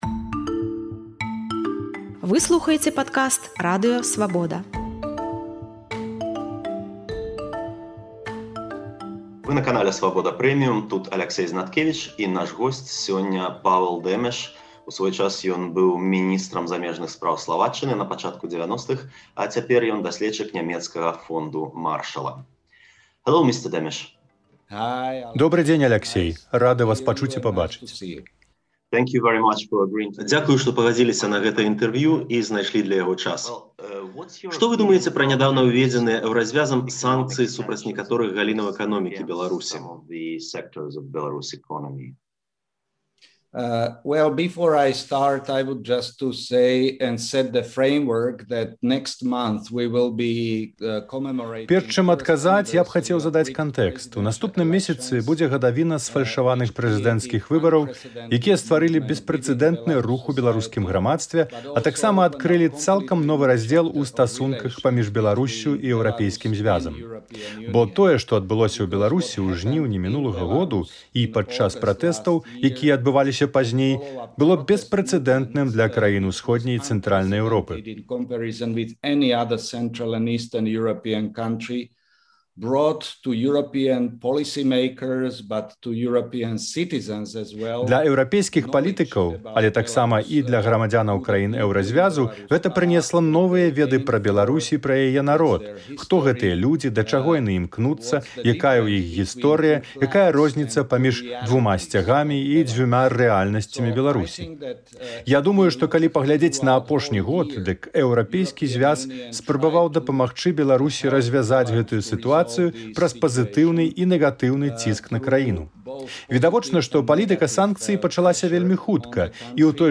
Павал Дэмэш — дасьледчык Нямецкага фонду Маршала, міністар замежных спраў Славаччыны на пачатку 1990-х гадоў. У гутарцы з Радыё Свабода ён разважае пра санкцыі Эўразьвязу, пра нестандартнае мысьленьне і садызм Аляксандра Лукашэнкі і пра тое, як Захад можа дамовіцца з Расеяй, каб вырашыць беларускі крызіс.